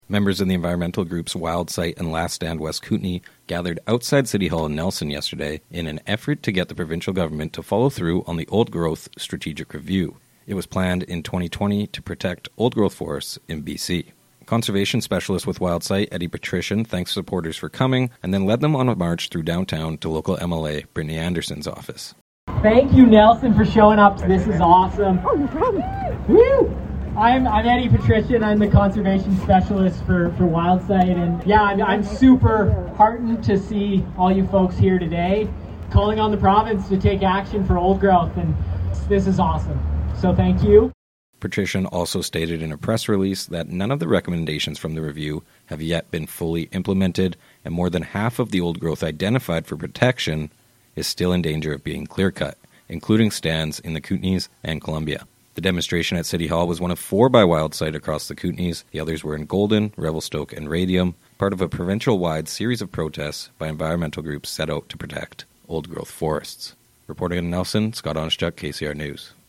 Wildsite-protest.mp3